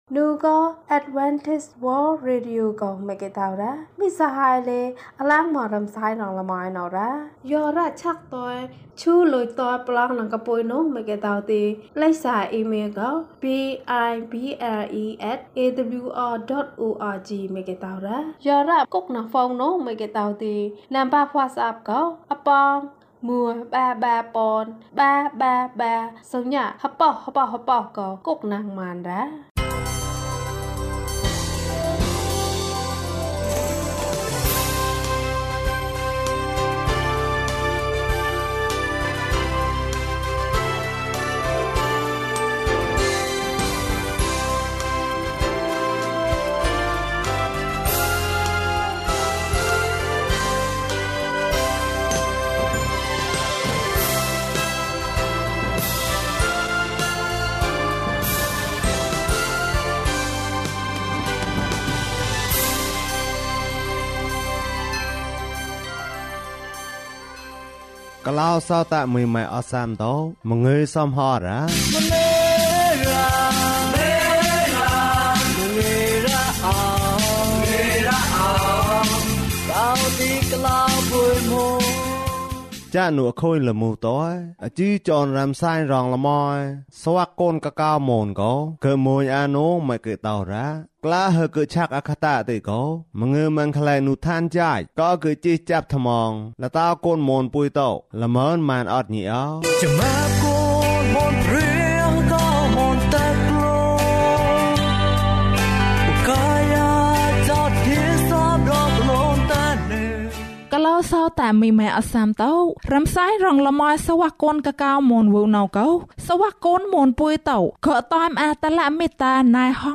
ယေရှု၏အသက်။ ကျန်းမာခြင်းအကြောင်းအရာ။ ဓမ္မသီချင်း။ တရား‌ဒေသနာ။